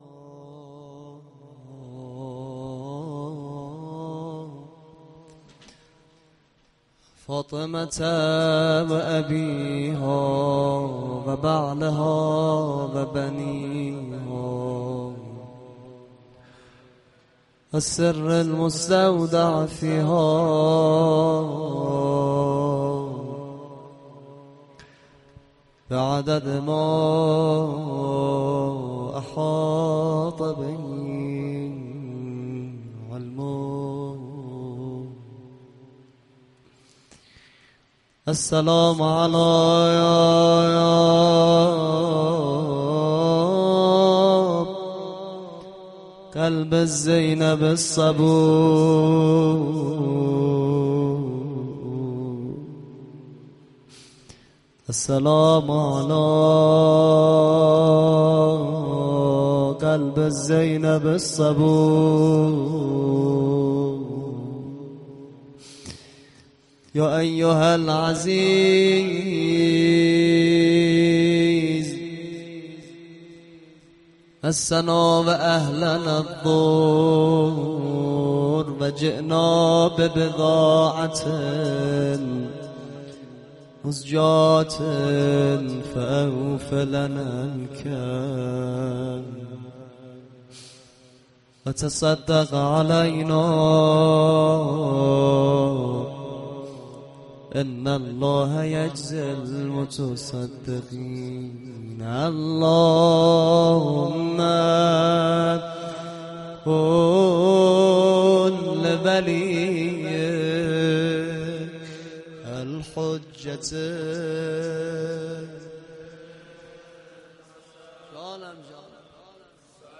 خیمه گاه - هئیت مهدویون - روضه